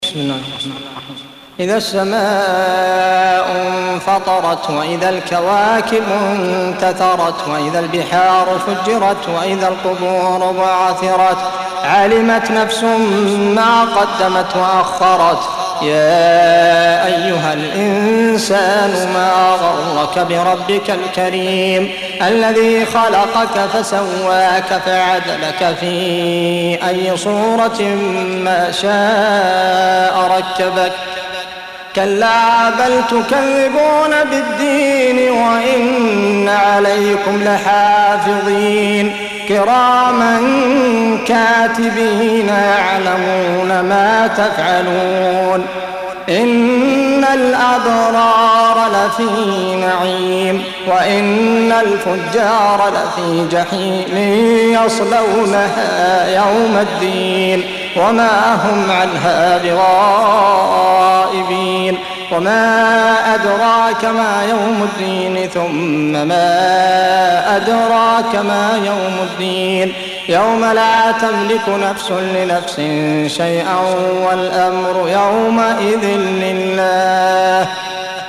Surah Sequence تتابع السورة Download Surah حمّل السورة Reciting Murattalah Audio for 82. Surah Al-Infit�r سورة الإنفطار N.B *Surah Includes Al-Basmalah Reciters Sequents تتابع التلاوات Reciters Repeats تكرار التلاوات